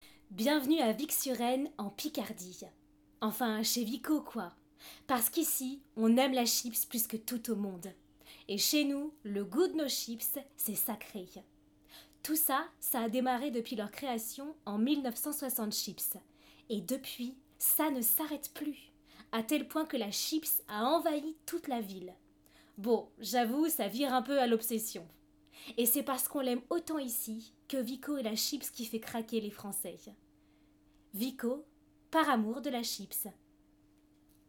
Maquette Vico (pub)